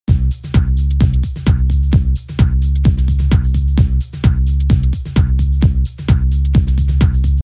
标签： 120 bpm House Loops Groove Loops 689.15 KB wav Key : Unknown
声道立体声